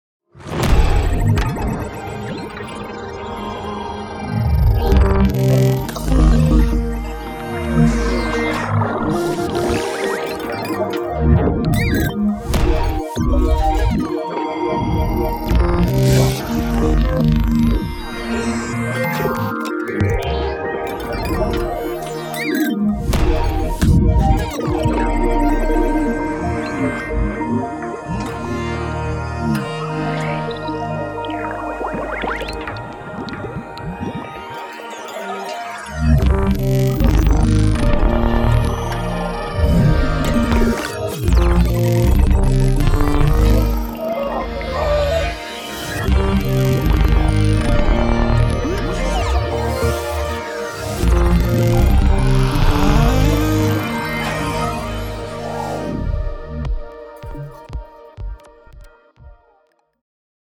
Indie Electronic